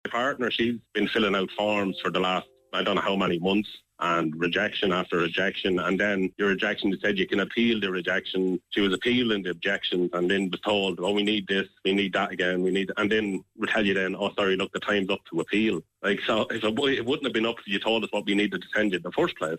Speaking on Kildare Today, he said his partner and himself have contacted five schools but have been told there are no places.